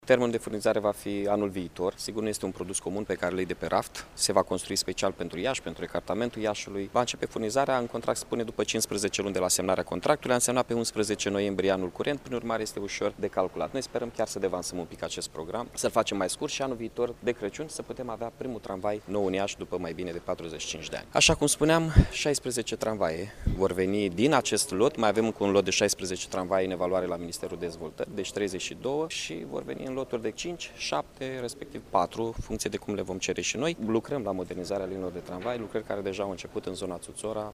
Licitaţia a fost de 150 de milioane de lei, a precizat primarul Iaşului, Mihai Chirica: